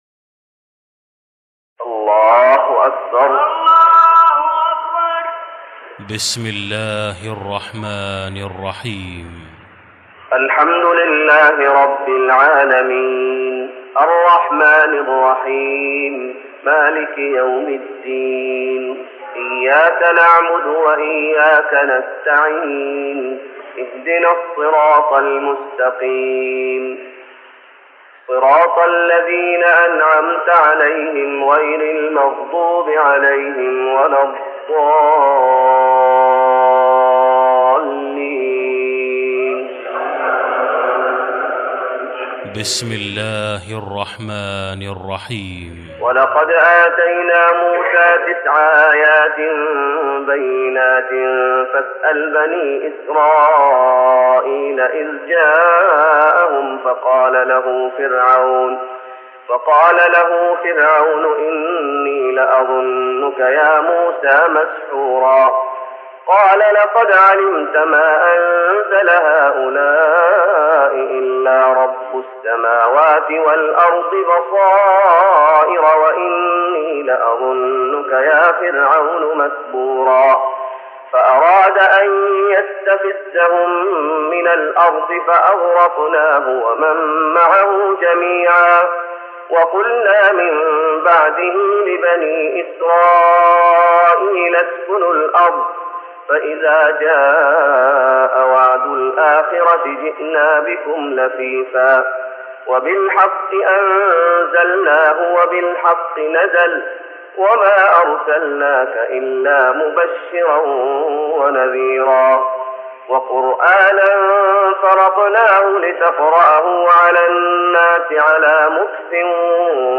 تراويح رمضان 1414هـ من سورة الإسراء (101-111) Taraweeh Ramadan 1414H from Surah Al-Israa > تراويح الشيخ محمد أيوب بالنبوي 1414 🕌 > التراويح - تلاوات الحرمين